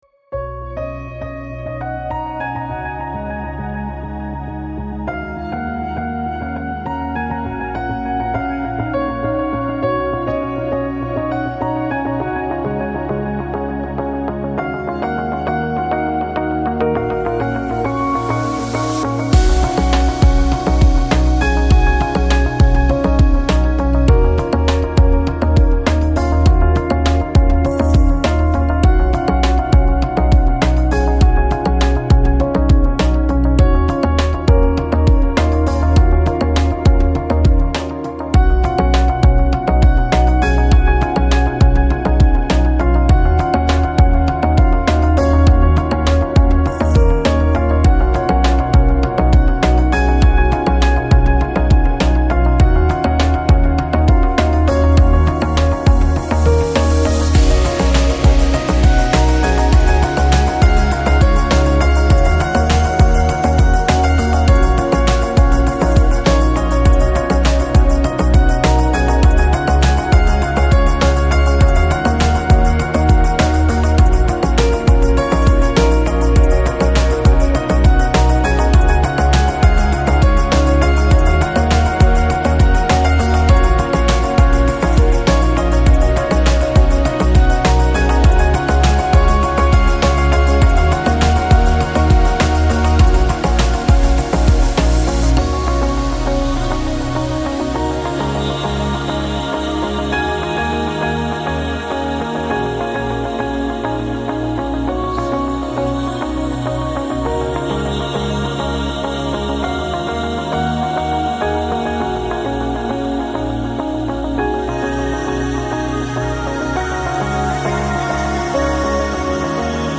Chill Out, Lounge, Downtempo, Balearic, Electronic